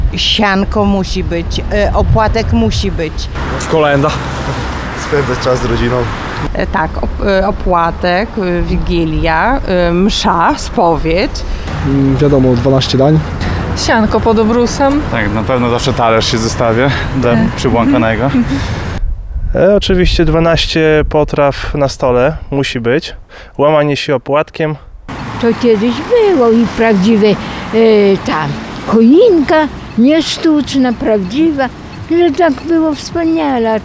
Święta to wyjątkowy czas, kiedy oprócz prezentów i spędzania czasu z bliskimi, mamy także okazję do przypomnienia sobie obyczajów, które towarzyszyły nam przy okazji obchodzenia świąt Bożego Narodzenia. -12 potraw, łamanie się opłatkiem i sianko pod obrusem – to tylko niektóre z tradycji, które wciąż są kultywowane w domach osób zapytanych na ulicach Suwałk.